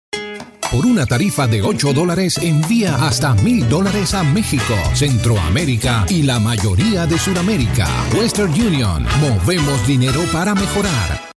TV Commercial
International Neutral Accent
Middle Aged
Wester Union TV Commercial.mp3